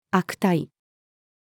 悪態-female.mp3